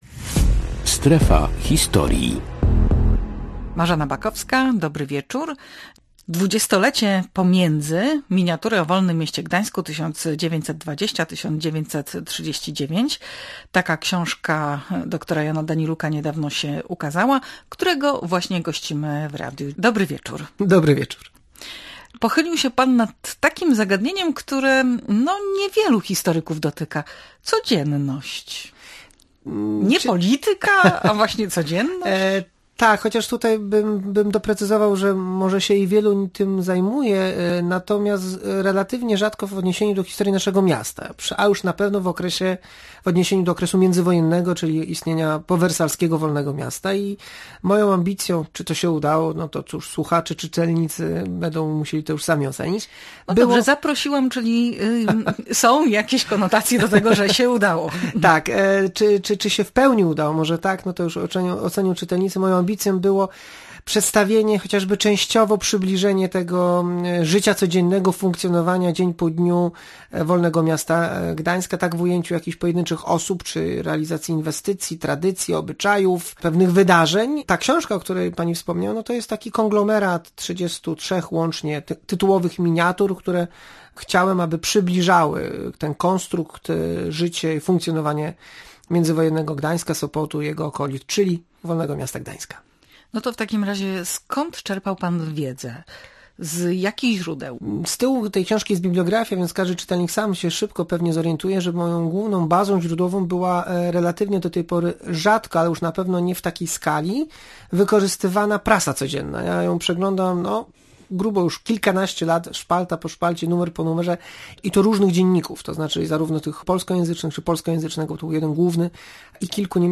Jak wyglądało życie codzienne w Gdańsku 100 lat temu? Rozmowa o książce „Dwudziestolecie pomiędzy”